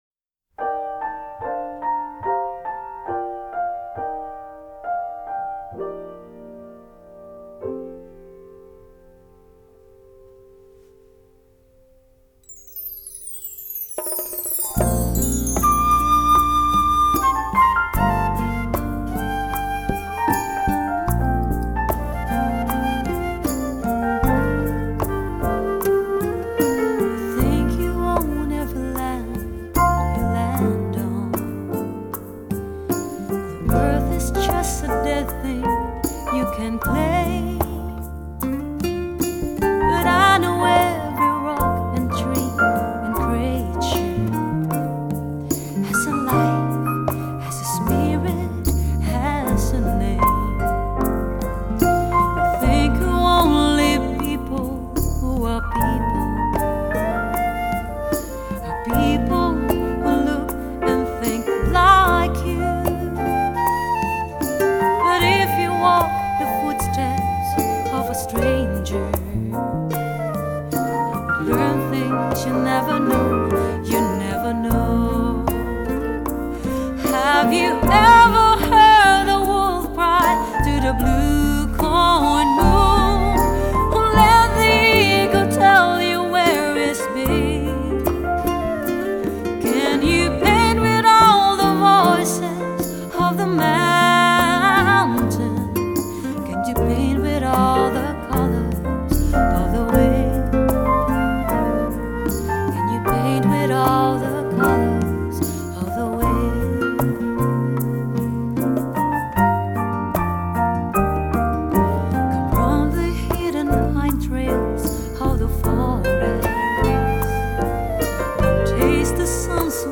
Pop&Jazz
由女声演绎的十首情歌首首经典，曲曲动人。
人声及乐器清晰重现，其空气感、暖意、鲜明的层次，把此碟推至ＨＩ－ＦＩ颠峰。